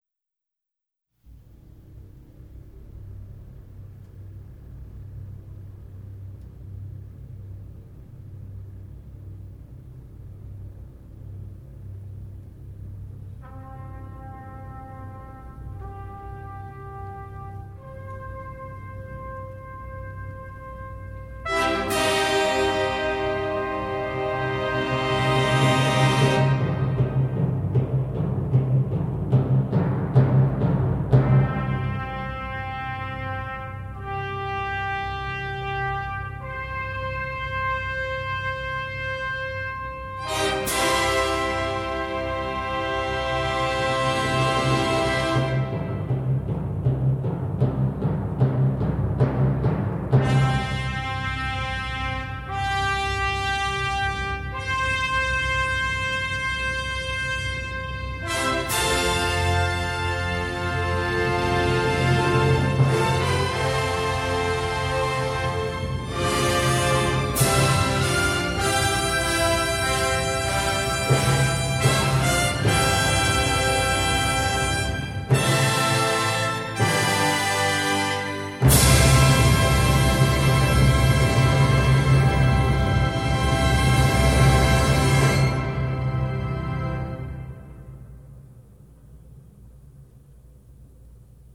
音诗以管风琴把低沉的C音延长了四个小节而开始，由此引出了大自然的动机，四支小号奏出C-G-C,接着是
大调和小调戏剧性地交替，由定音鼓敲出雷鸣般的三连音。
演奏稳重扎实，音响坚实有力，铜管组声音洪亮辉煌。